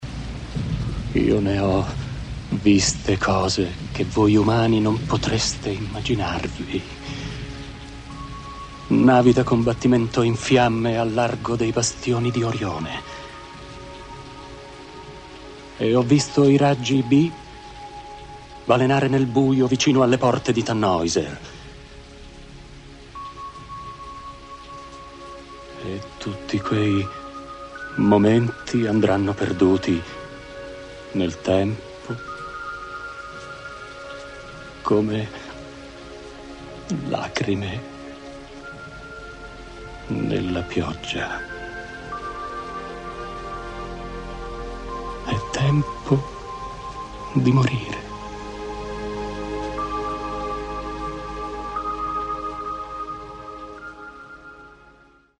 VERSIÓN ITALIANA